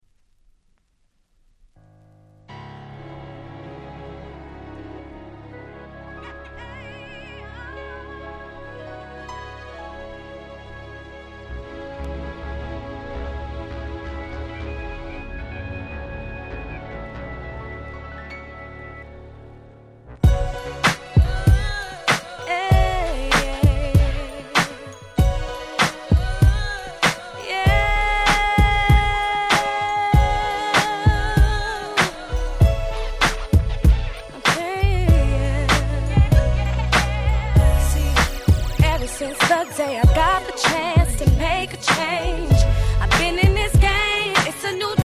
08' Smash Hit Hip Hop !!